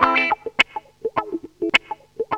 GTR 25 AM.wav